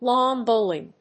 アクセントláwn bòwling